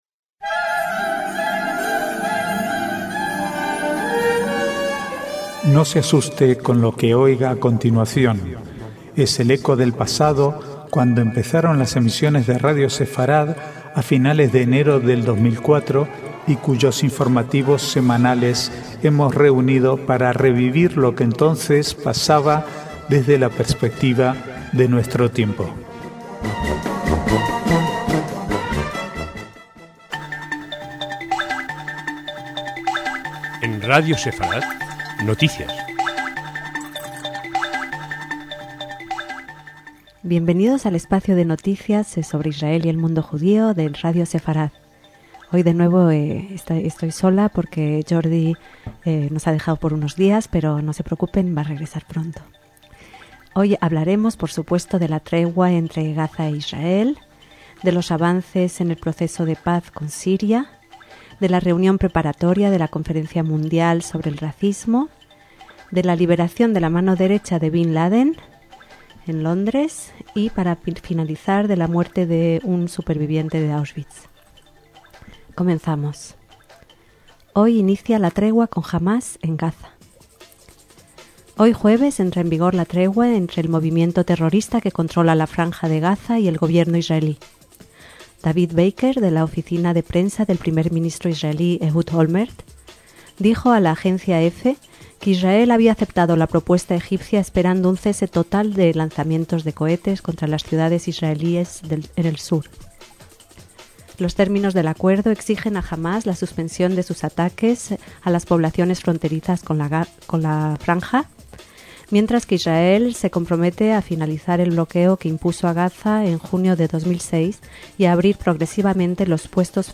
Archivo de noticias del 19 al 24/6/2008